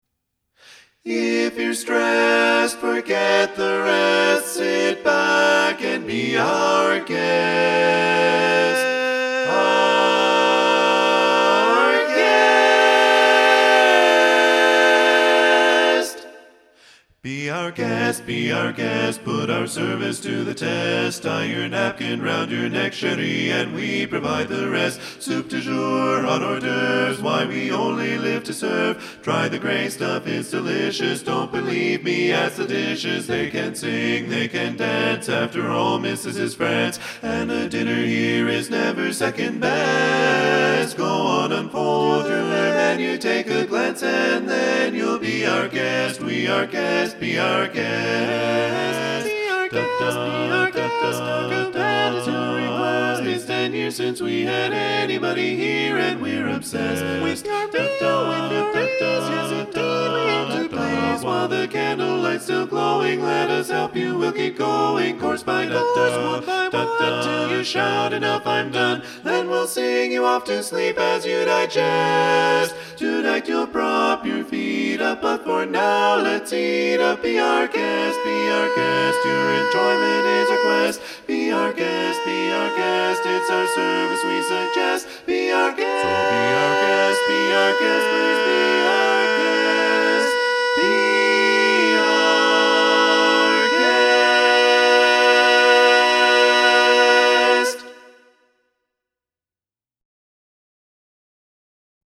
Up-tempo
B♭ Major
Full Mix